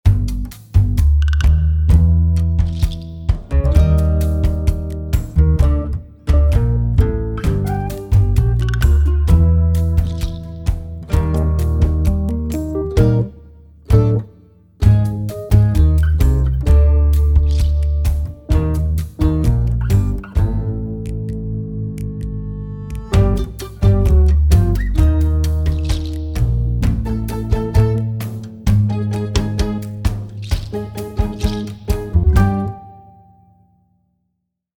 farcical, acoustic, tango